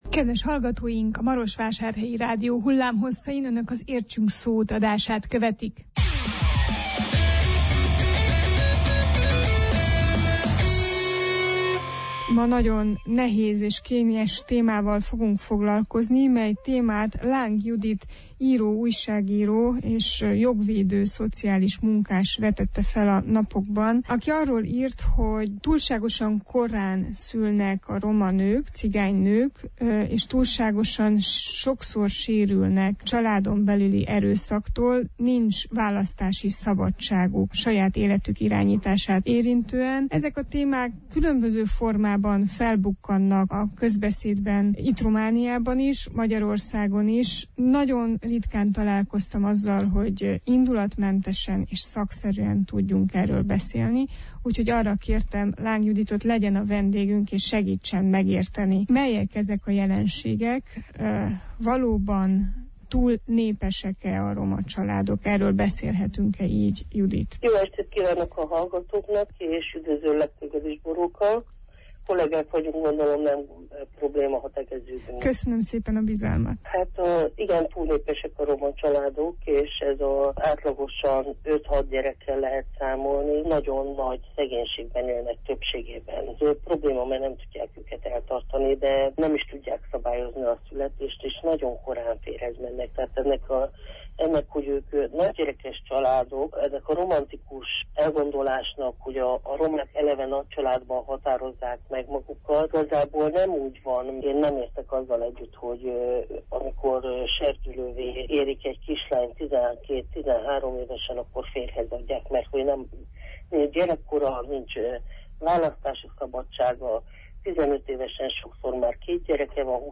Interjúnkban kísérletet teszünk arra is, hogy összevessük a különböző régiók romákat érintő problémáit, hogy átgondoljuk: melyek a szociális segítségnyújtás valóban hatékony formái.